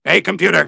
synthetic-wakewords
ovos-tts-plugin-deepponies_Soldier_en.wav